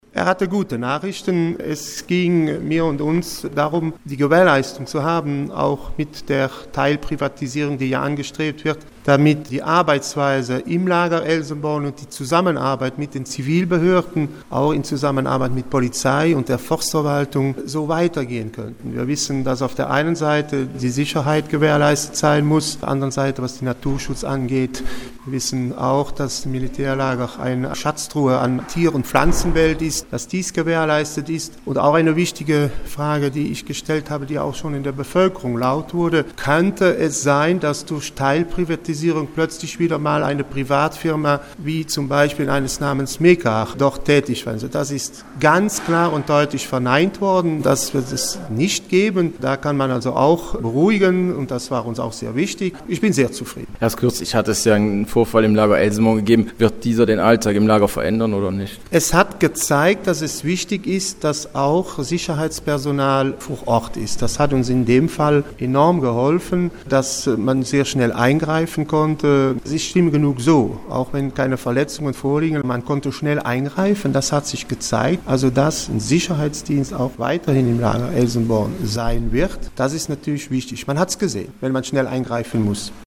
Bütgenbachs Bürgermeister Emil Dannemark gefragt, ob der General-Major gute Nachrichten für die Gemeinde hatte: